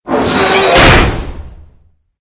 CHQ_VP_collapse.ogg